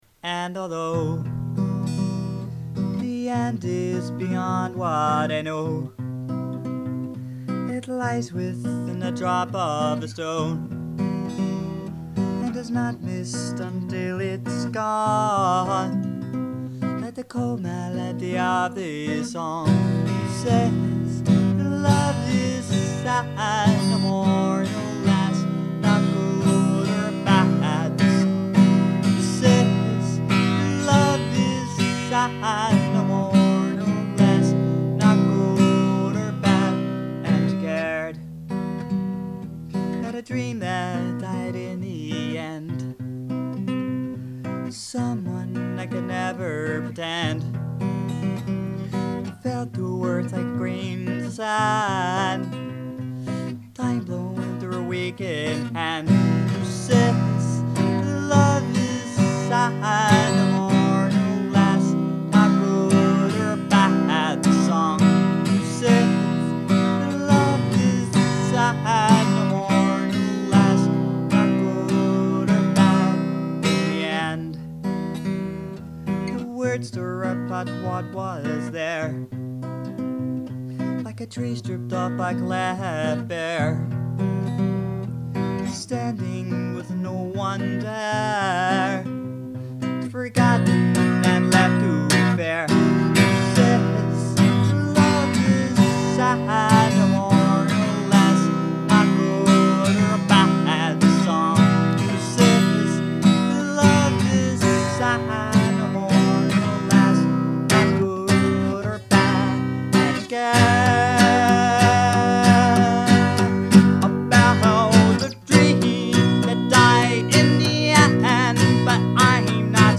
Music - I just bought a cheap microphone and have recorded two songs. They sound a bit ugly right now, but I will keep working on them Here are the songs: